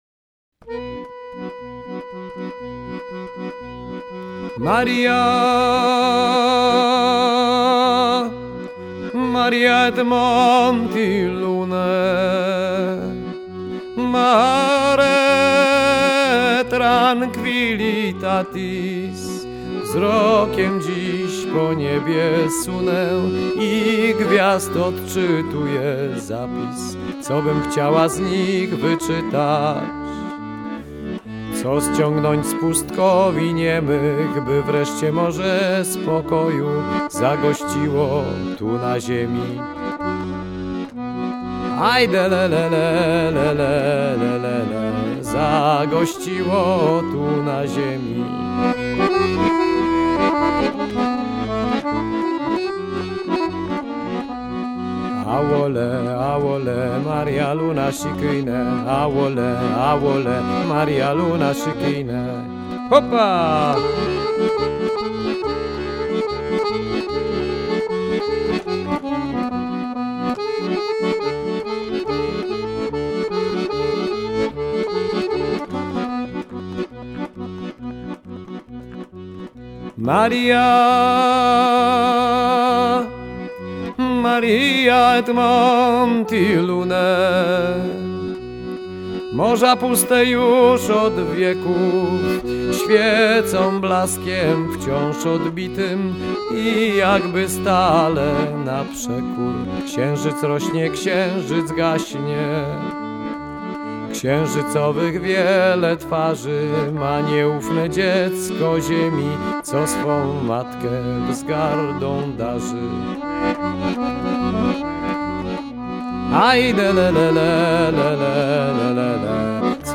pieśń księżycowa